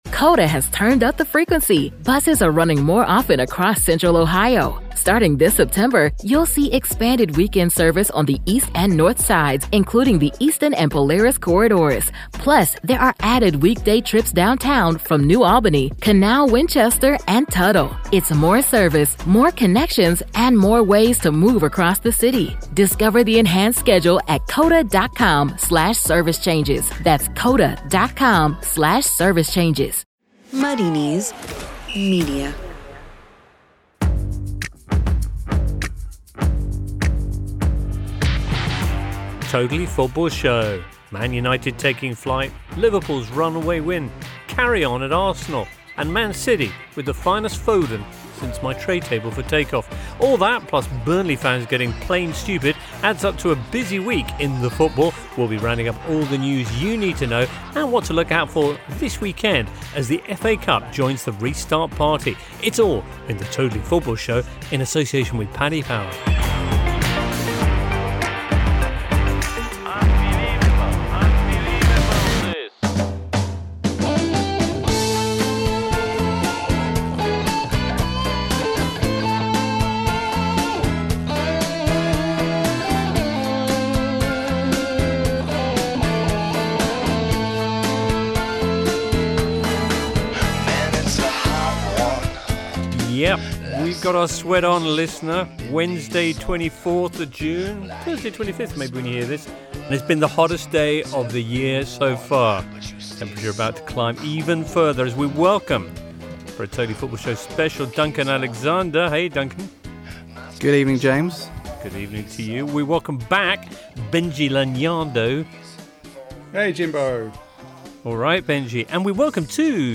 PART 4: A conversation on representation within sports media